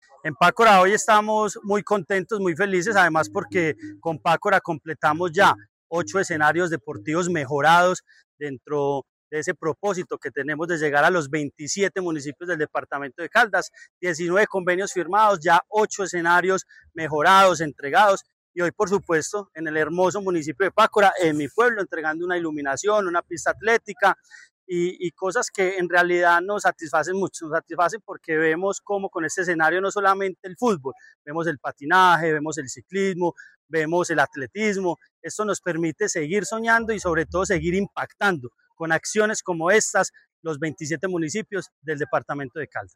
Andrés Duque Osorio, secretario de Deporte, Recreación y Actividad Física de Caldas